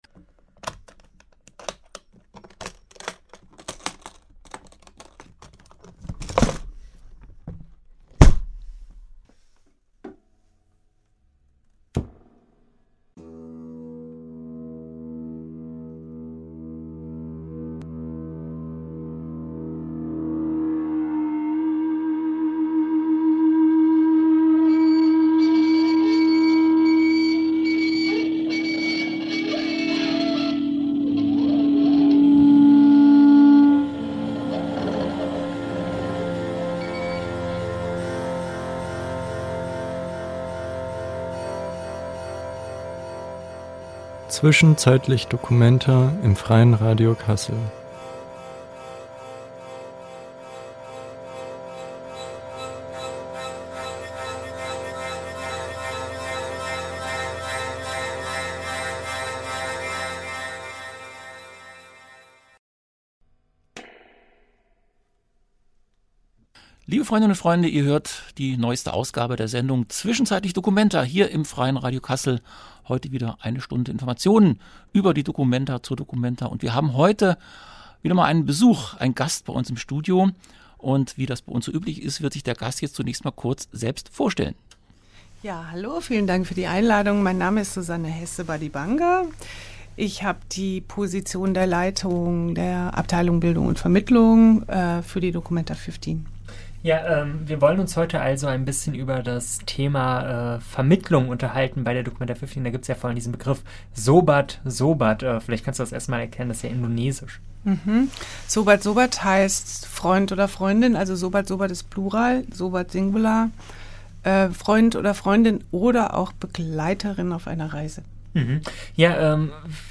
Im interview zieht sie ein selbstkritisches Zwischenfazit.